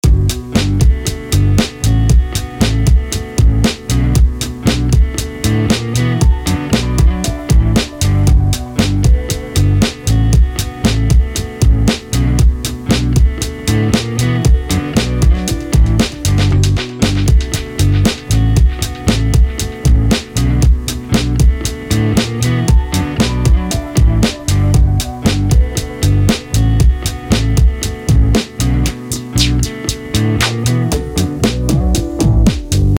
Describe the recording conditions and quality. Got a Fender Mustang Micro headphone amp sim thing to connect my guitar and bass directly into the Digitakt.